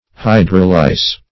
hydrolise - definition of hydrolise - synonyms, pronunciation, spelling from Free Dictionary